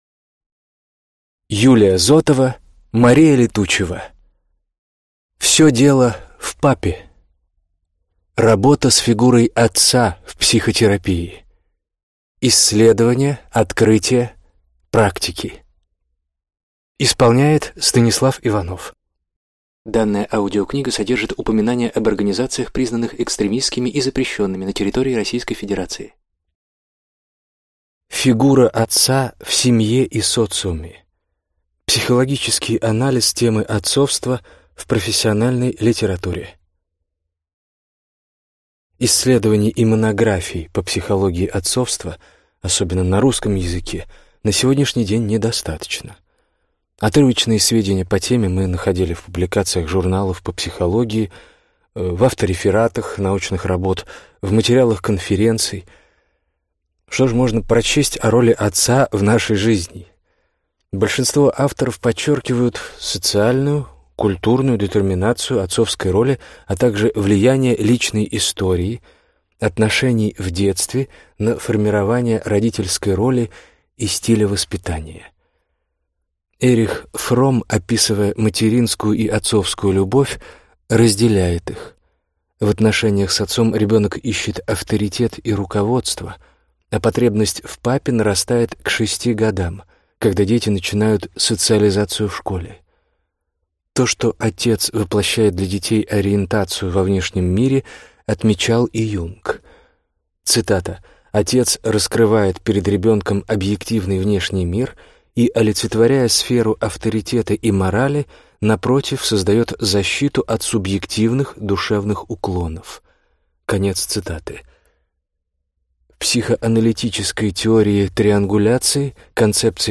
Аудиокнига Все дело в папе. Работа с фигурой отца в психотерапии. Исследования, открытия, практики | Библиотека аудиокниг